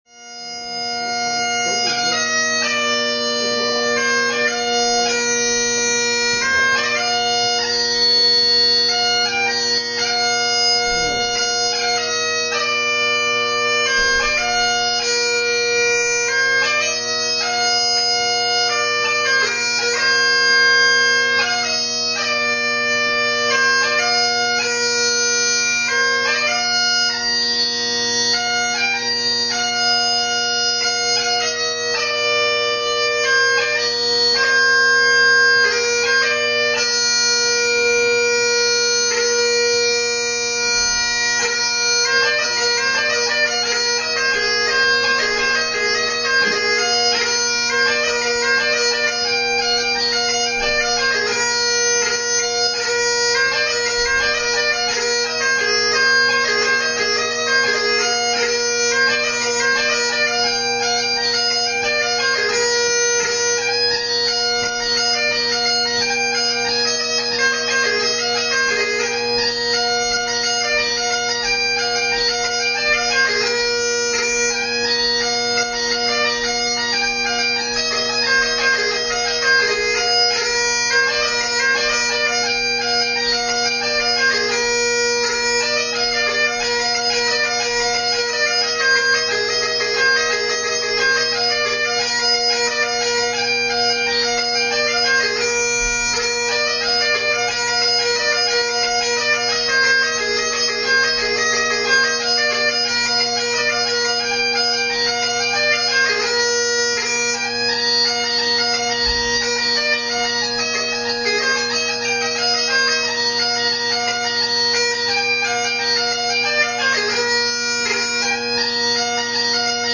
Extracts from 2005 competition
Slow Air, Hornpipe and Jig